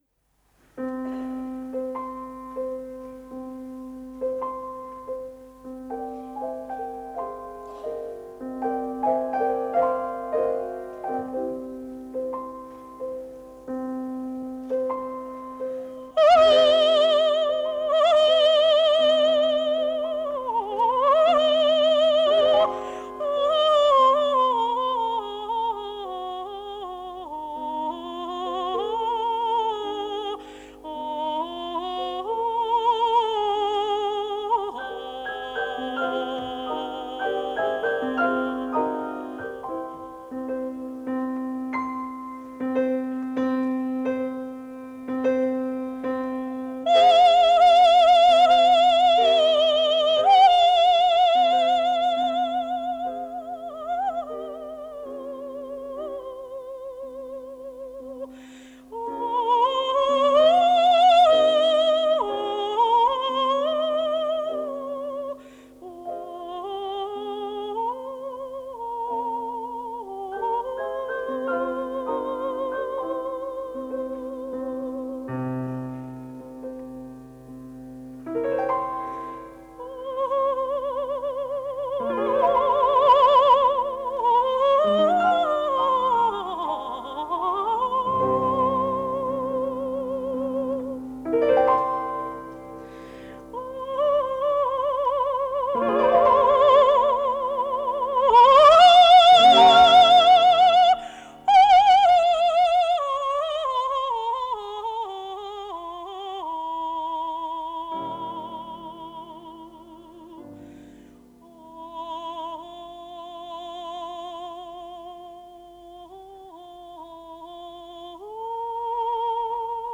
20 - Зара Долуханова - Морис Равель. Вокализ в форме Хабанеры